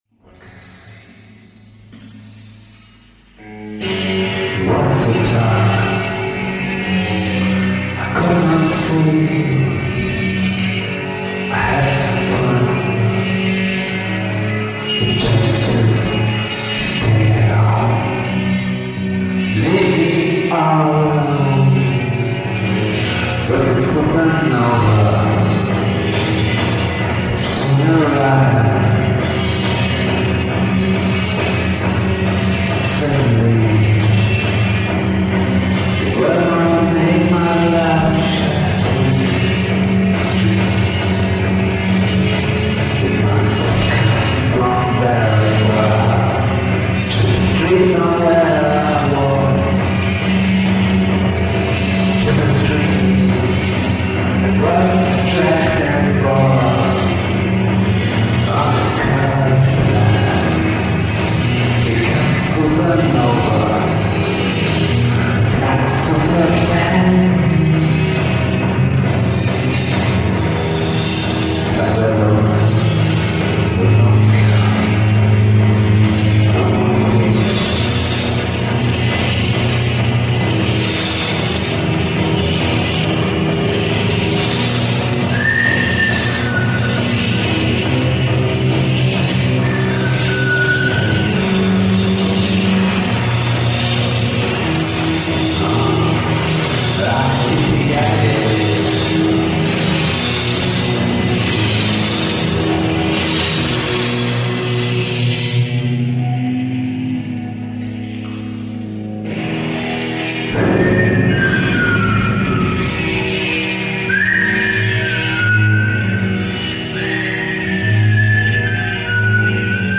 ISDN STEREO